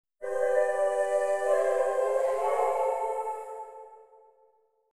各エフェクトの Mix と、Wet音だけを抽出したものです。
Lexicon Wet
ボーカル・ホール系のプリセットを、ほぼそのまま AUX に置き、リターン量が同じになるように設定。
Lexicon_wet.mp3